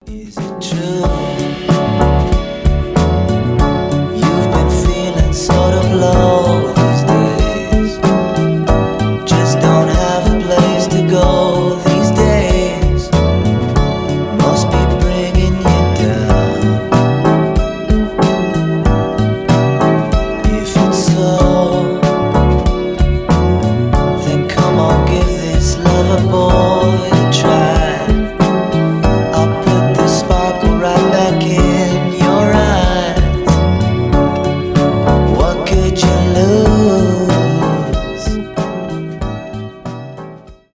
рок , tik-tok , alternative , lo-fi , indie rock